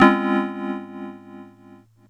G#MIN9.wav